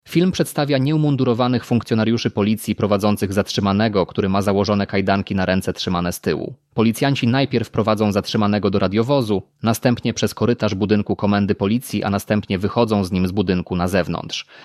Audiodeskrypcja (plik mp3) - plik mp3